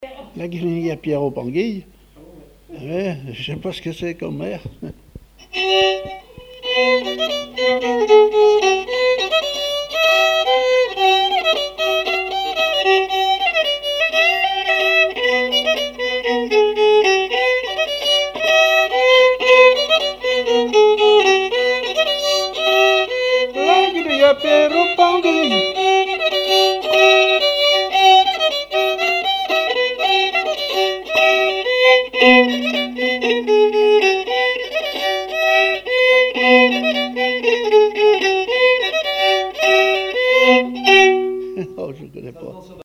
Divertissements d'adultes - Couplets à danser
branle : avant-deux
répertoire musical au violon
Pièce musicale inédite